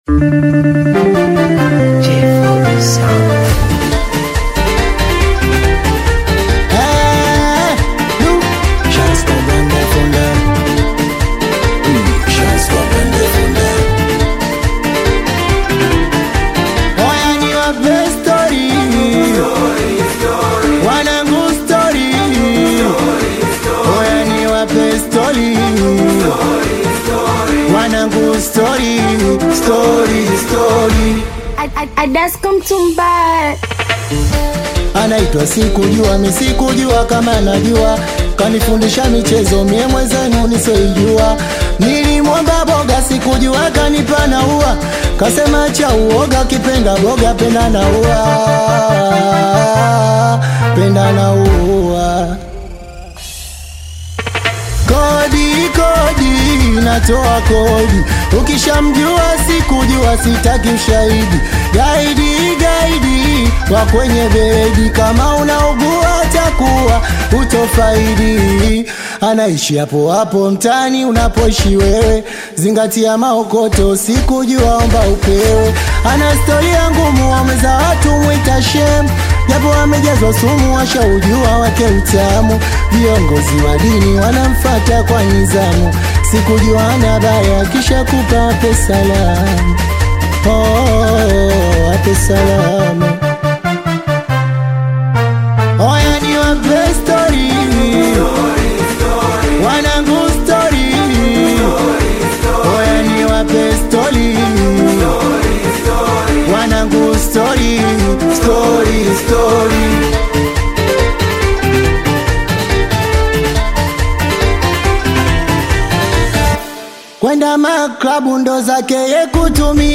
Singeli music track
Tanzanian Bongo Flava
Singeli song